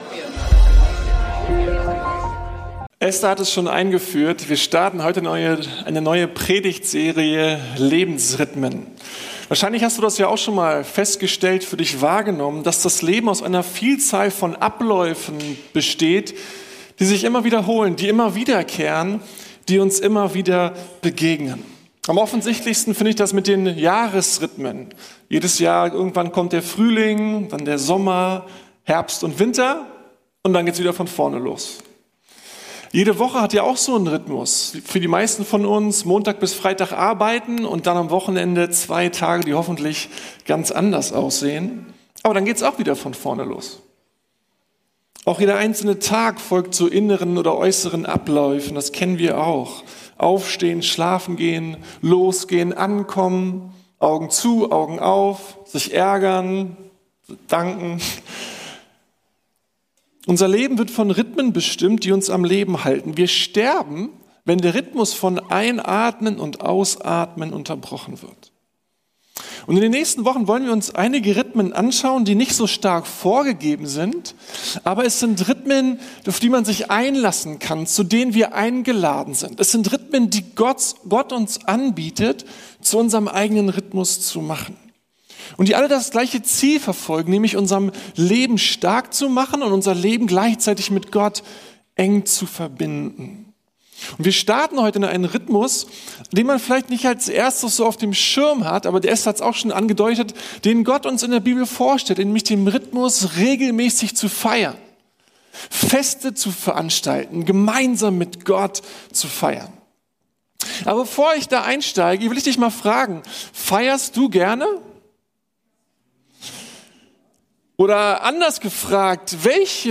Lebensrhythmen: Feiern ~ Predigten der LUKAS GEMEINDE Podcast